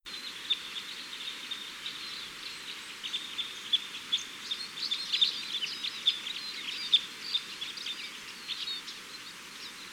kruisbek
🔭 Wetenschappelijk: Loxia curvirostra
♪ contactroep
kruisbek_roep.mp3